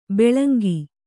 ♪ beḷngi